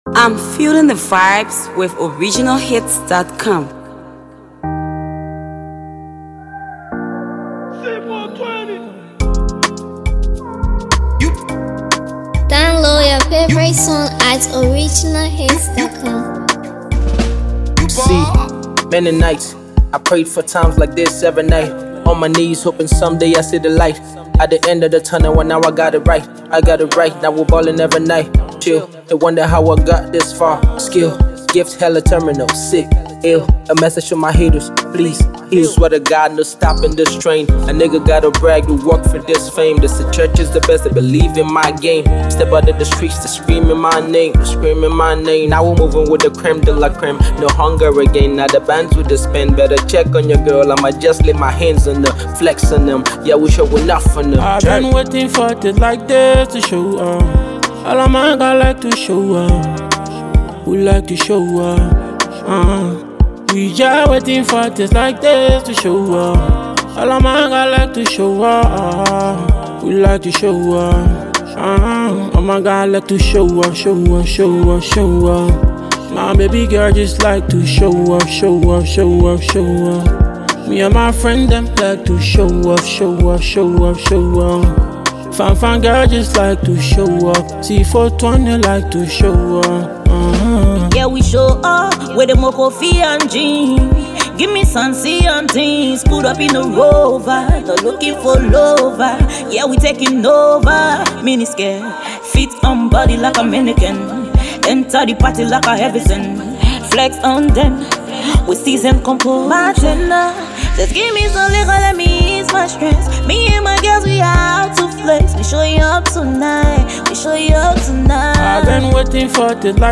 This jam is a complete anthem.